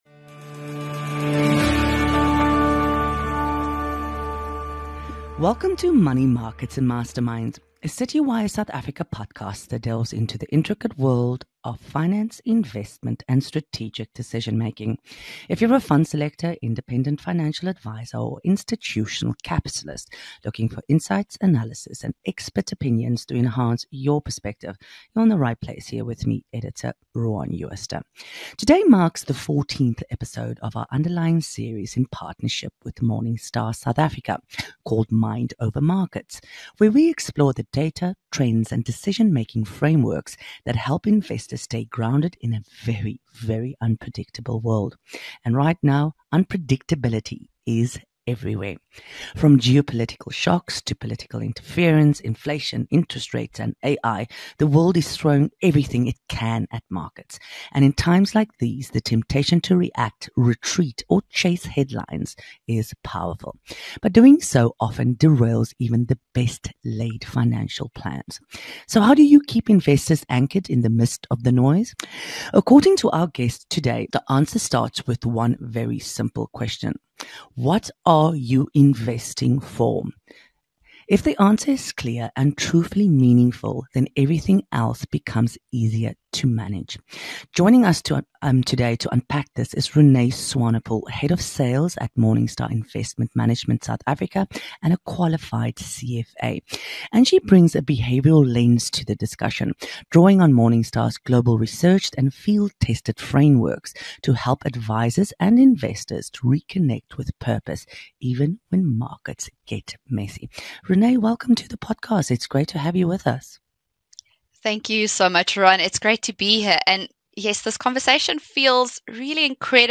for a conversation that brings the behavioural side of investing into sharp focus.